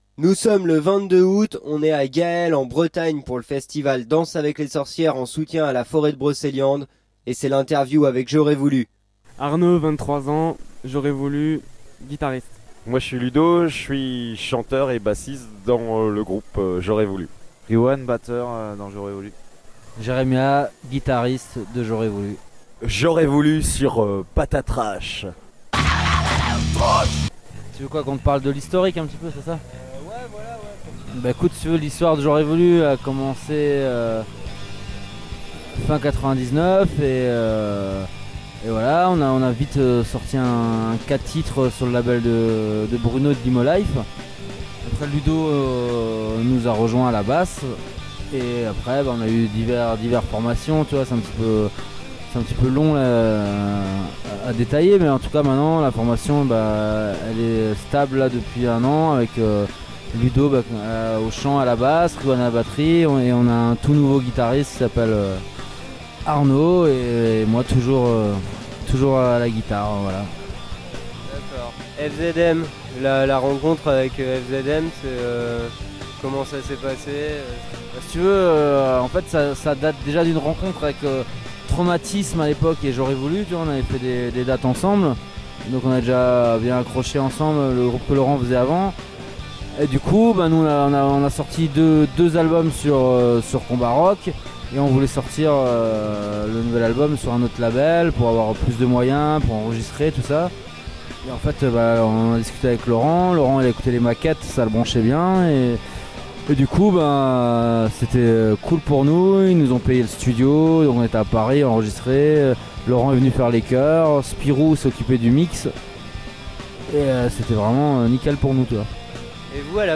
ITW avec J'aurais Voulu...